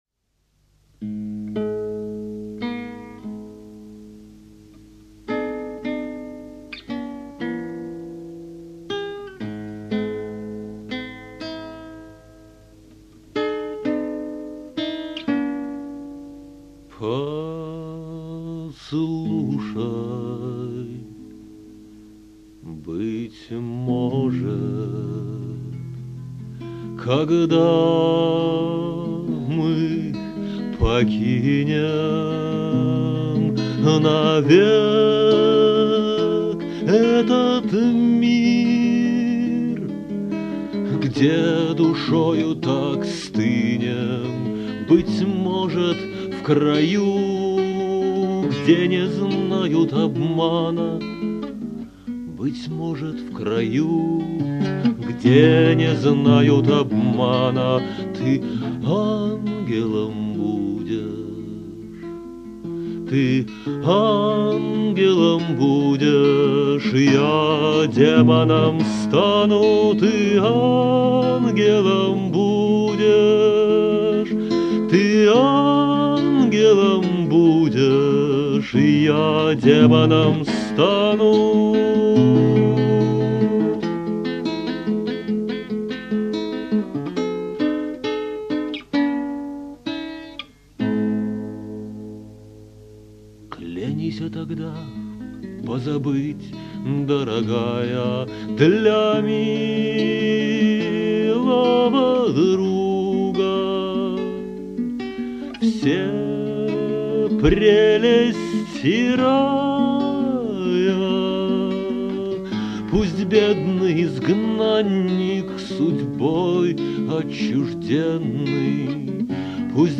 песни 1992-97 гг. в исполнении автора.